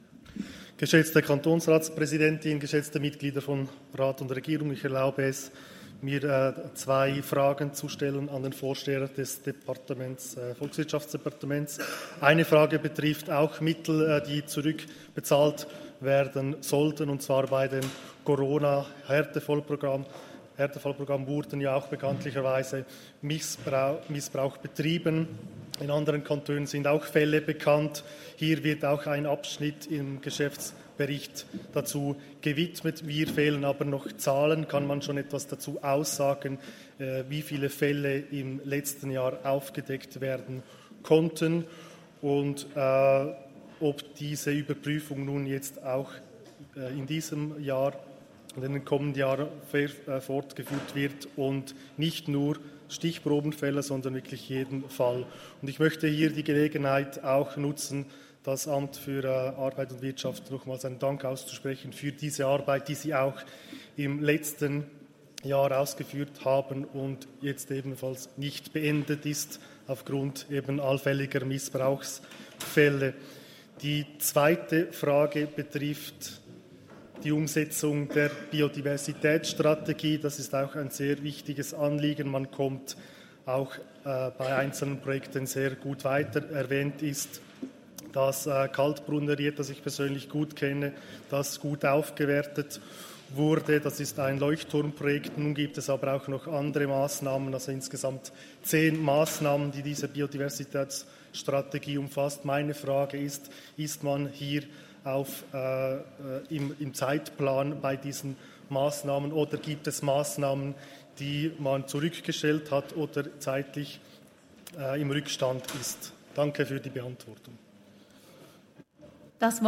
Session des Kantonsrates vom 12. bis 14. Juni 2023, Sommersession
12.6.2023Wortmeldung